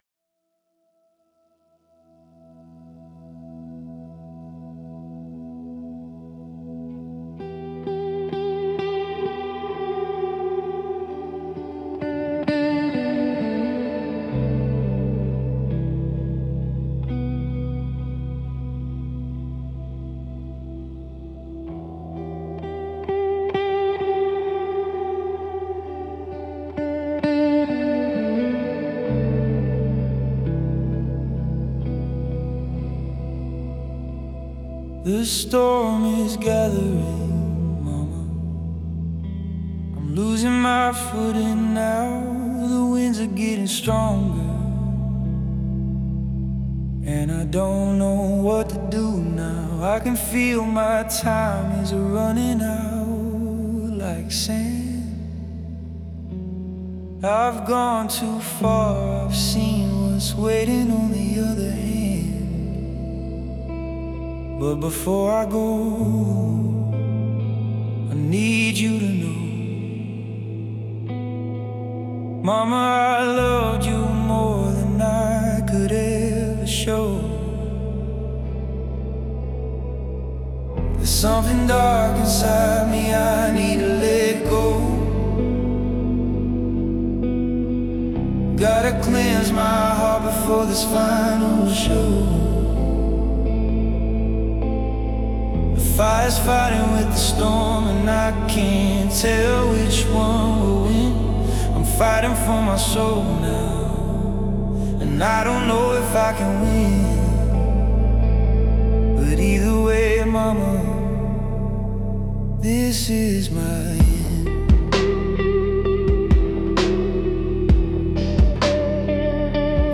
Indie Rock • Emotional • Dramatic • Redemption • End-of-Life